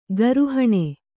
♪ garuhaṇae